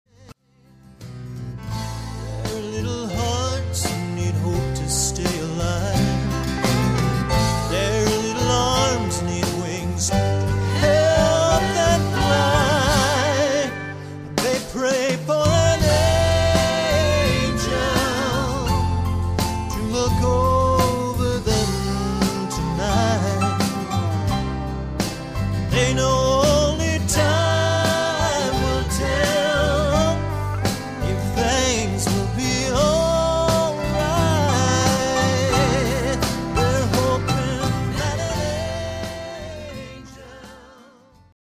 Vocals and Guitar
Keyboards and background vocals
Drums
Bass Guitar